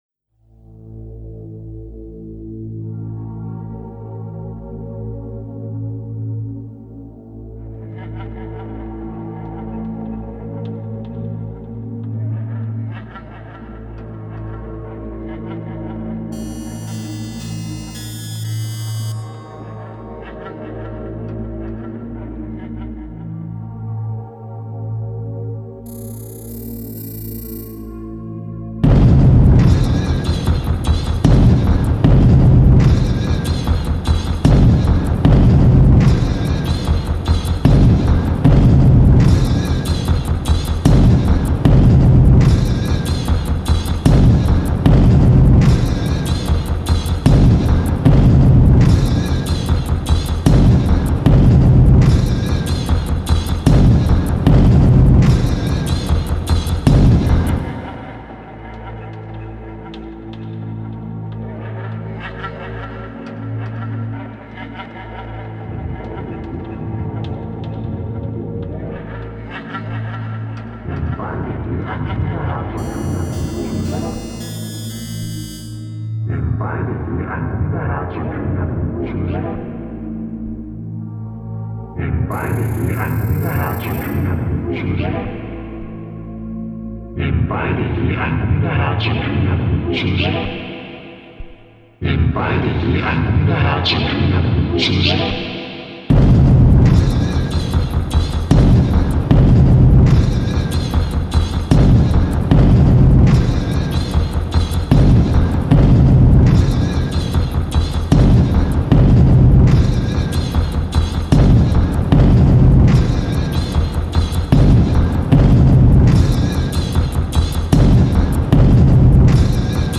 literature, ancient rites and modern electronics
innovative underground electronic music